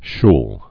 (shl, shl)